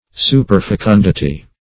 superfecundity - definition of superfecundity - synonyms, pronunciation, spelling from Free Dictionary
Search Result for " superfecundity" : The Collaborative International Dictionary of English v.0.48: Superfecundity \Su`per*fe*cun"di*ty\, n. Superabundant fecundity or multiplication of the species.